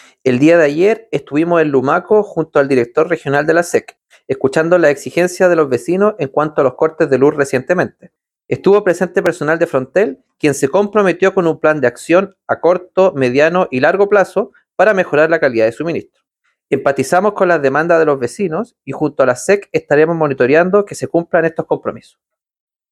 Quien también habló sobre los reclamos por los cortes de energía eléctrica fue el seremi de Energía en La Araucanía, Camilo Villagrán, quien se comprometió a fiscalizar los compromisos asumidos por la compañía encargada de la distribución de electricidad en esa zona.